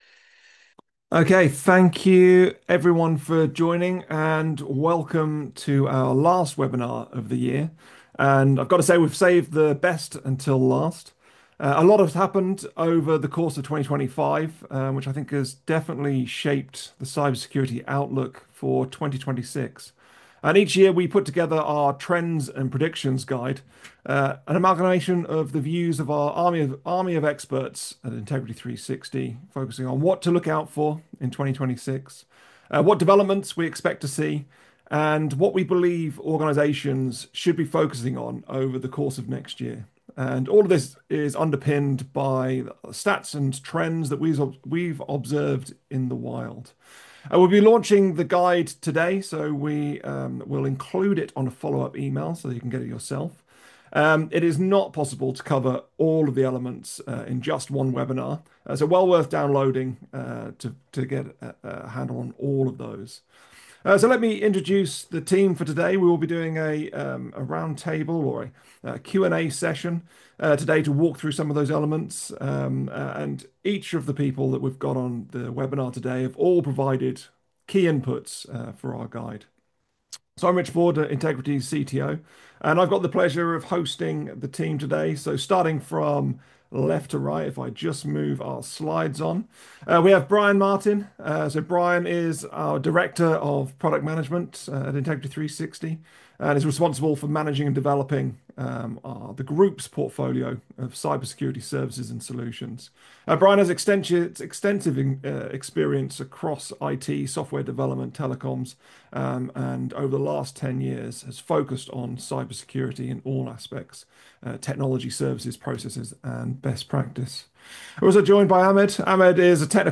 On-demand webinar 'Resilience redefined: Navigating the human-AI era — Integrity360’s 2026 cyber security trends and predictions'
Resilience redefined: Navigating the human-AI era — Integrity360’s 2026 cyber security trends and predictions This is the recording of our live webinar held on December 11th, 2025 Listen to audio View on demand recording